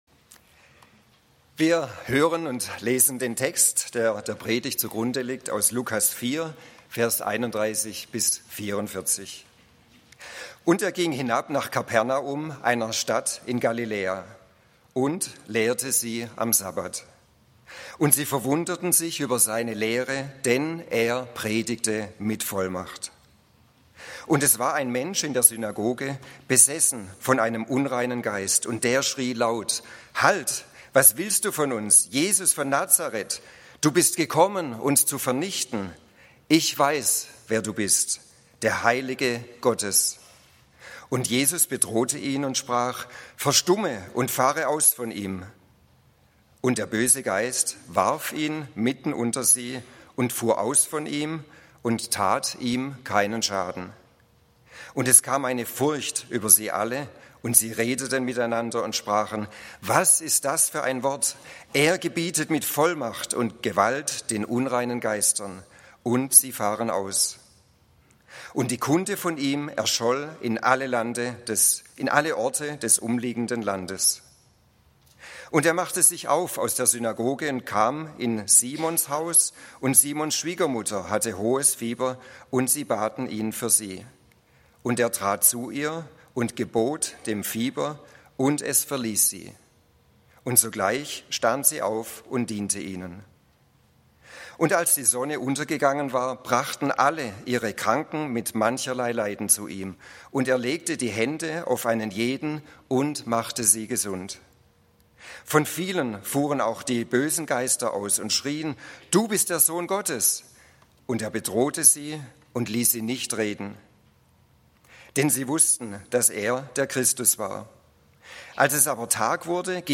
Gott ist genug - immer und in allem - Bibelstunde